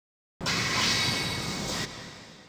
AV_Machine_FX
AV_Machine_FX.wav